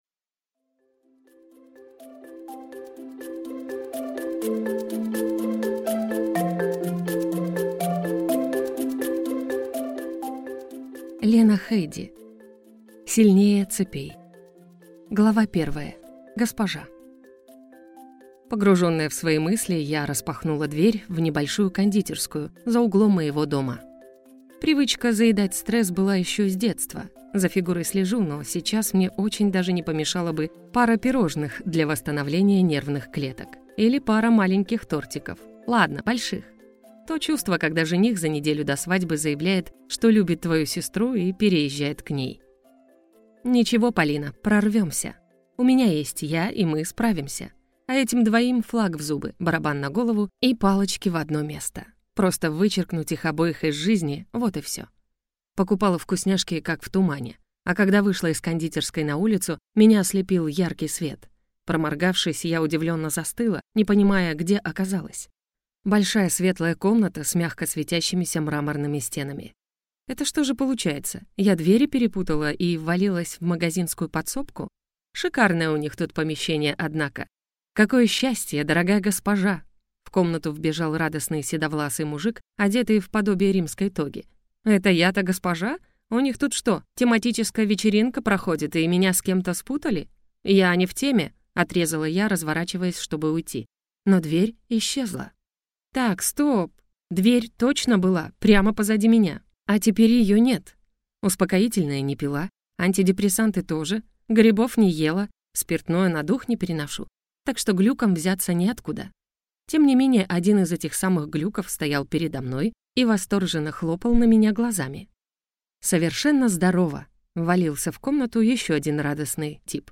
Аудиокнига Сильнее цепей | Библиотека аудиокниг